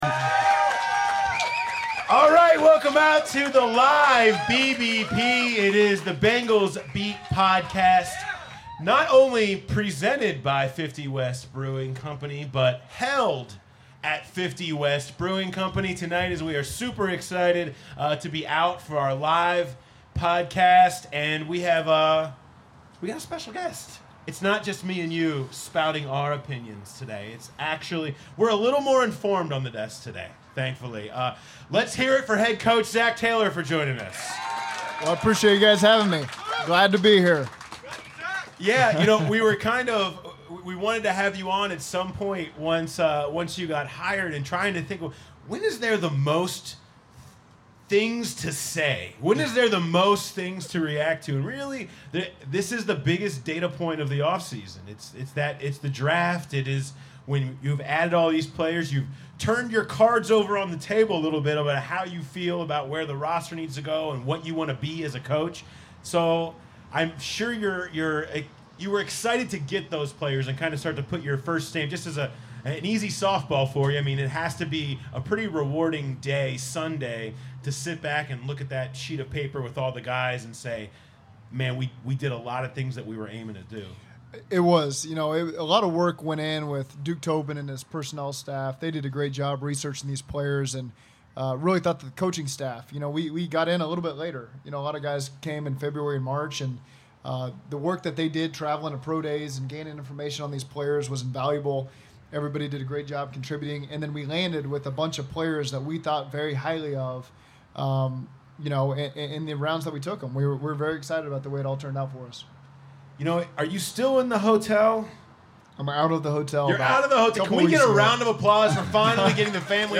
Apologies for technical difficulties caused by a windy night that arrive after the 13-minute mark.